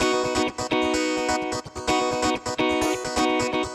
VEH3 Electric Guitar Kit 1 128BPM
VEH3 Electric Guitar Kit 1 - 5 B maj.wav